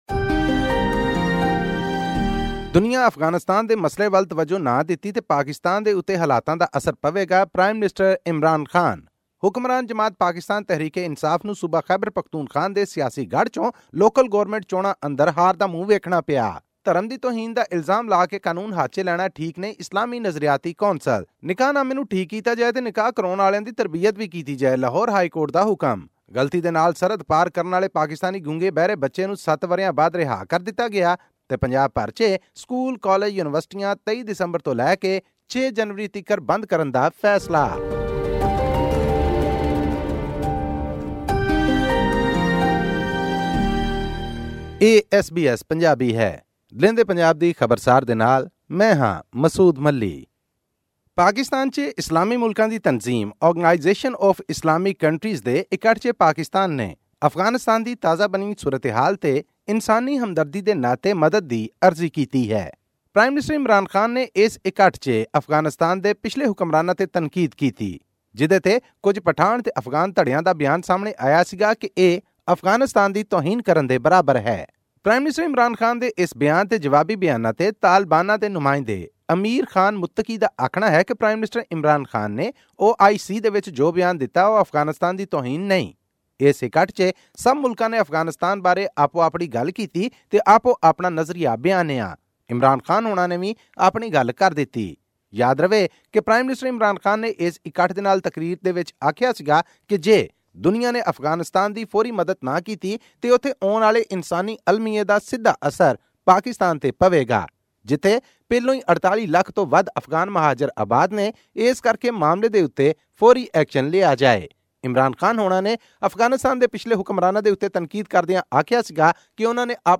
Pakistan hosted a conference of Muslim countries to establish financial assistance to prevent a humanitarian disaster in Afghanistan. This and more in our weekly news update from Pakistan.